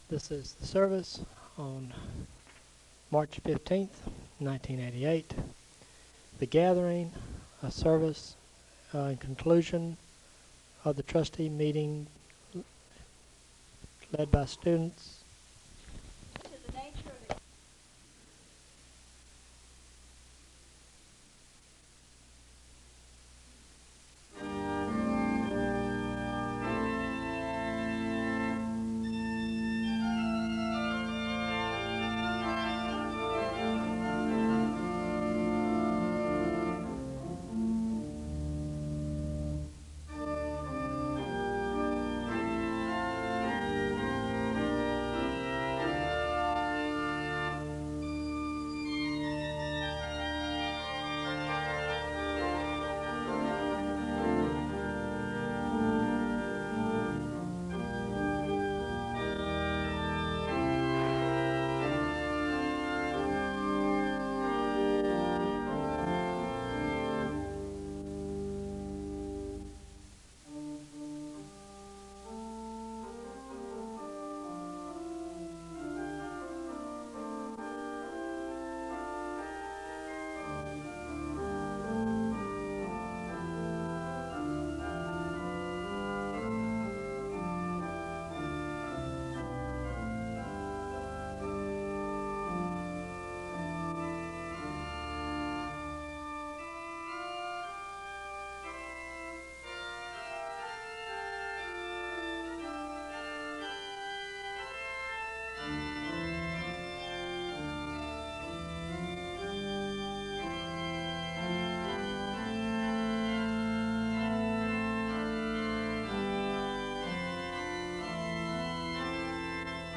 The service begins with organ music (0:00:00-0:06:33). There is a Scripture reading and a moment of prayer (0:06:34-0:10:51).
A welcome is given, and announcements are made (0:14:42-0:16:58).
There is a song of worship (0:20:20-0:24:03).
There is a congregational hymn (0:54:06-0:57:13).
The service concludes with organ music (1:01:58-1:03:52).